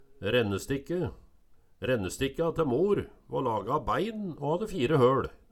Høyr på uttala Ordklasse: Substantiv hokjønn Kategori: Reiskap og arbeidsutstyr Attende til søk